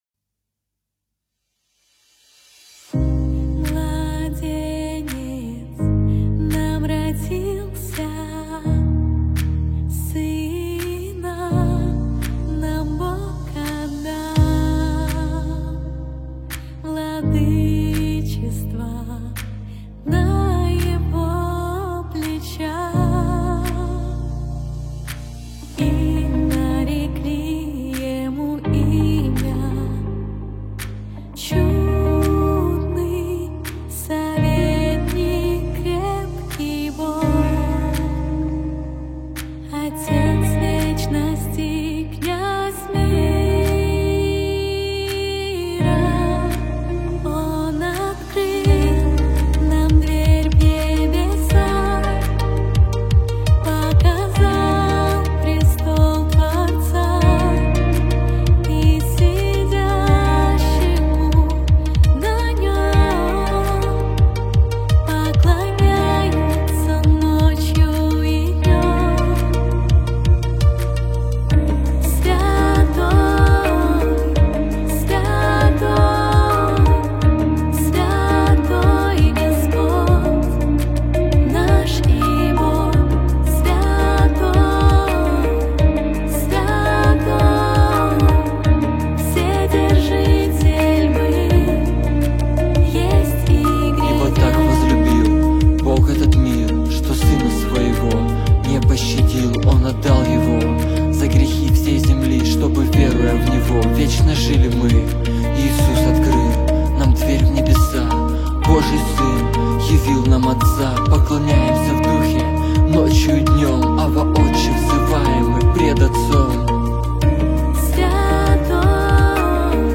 1075 просмотров 3293 прослушивания 236 скачиваний BPM: 83